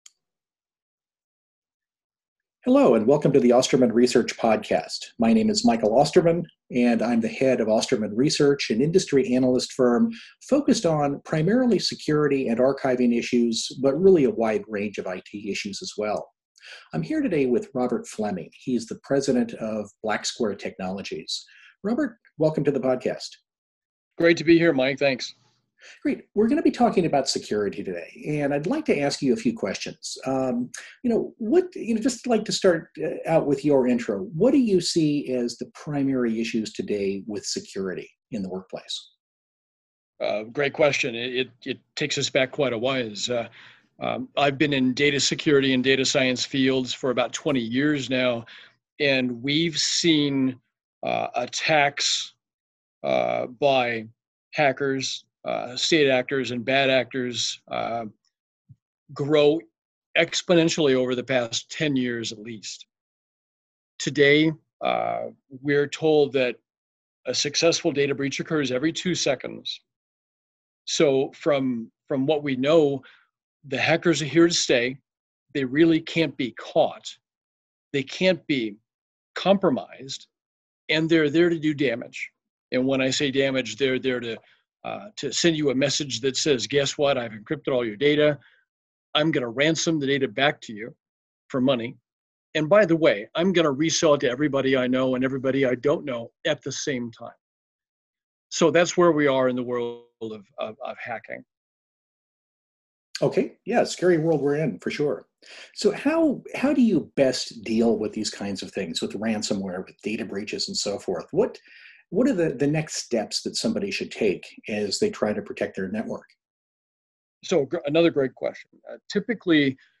A Conversation About Security